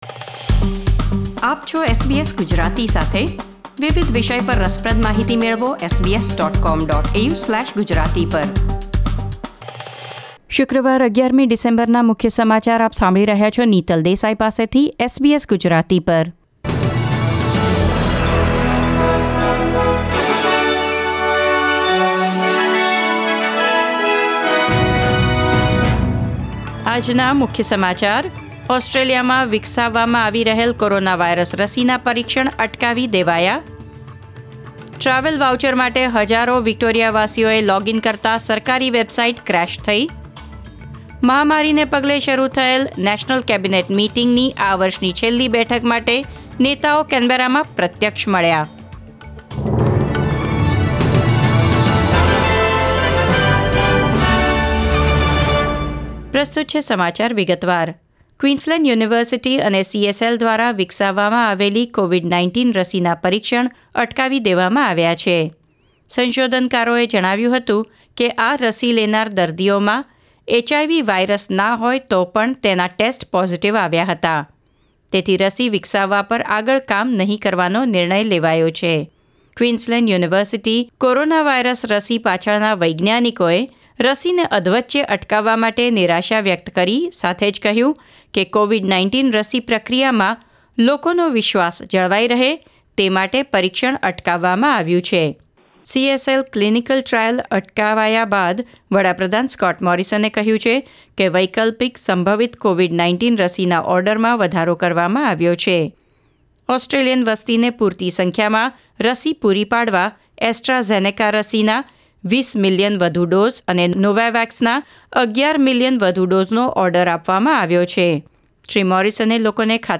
SBS Gujarati News Bulletin 11 December 2020